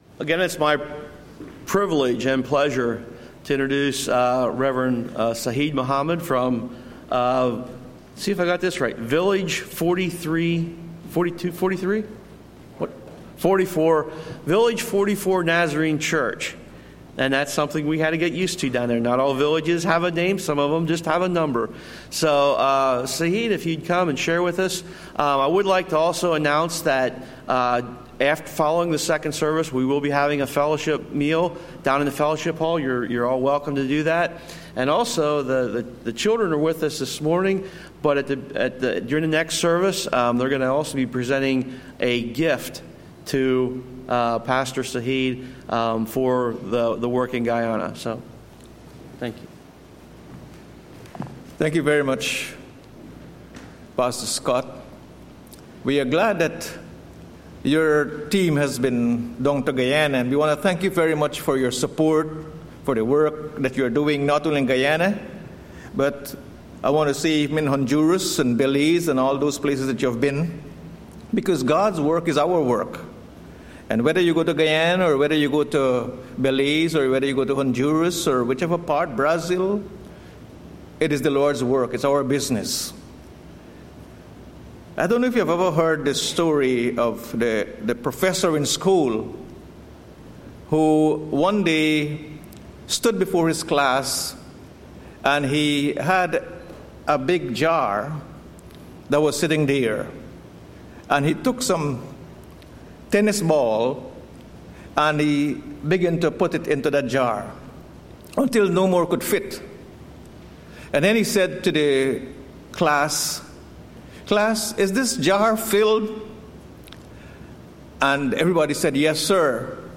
Sunday Missions